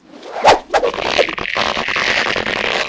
tailpull.wav